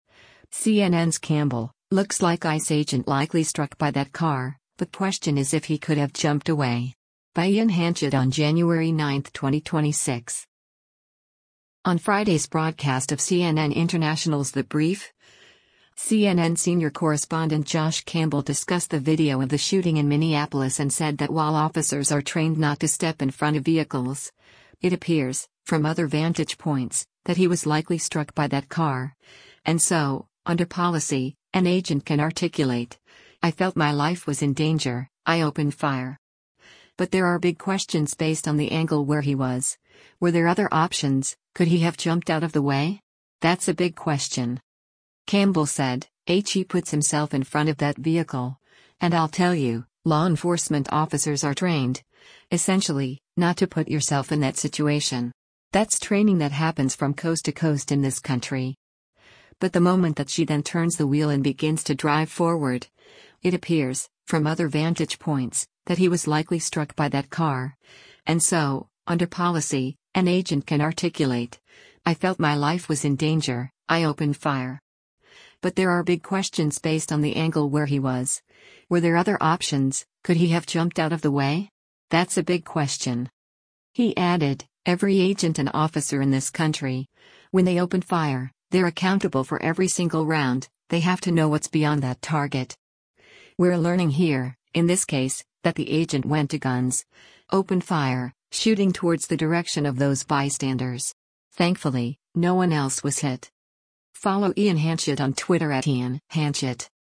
On Friday’s broadcast of CNN International’s “The Brief,” CNN Senior Correspondent Josh Campbell discussed the video of the shooting in Minneapolis and said that while officers are trained not to step in front of vehicles, “it appears, from other vantage points, that he was likely struck by that car, and so, under policy, an agent can articulate, I felt my life was in danger, I opened fire.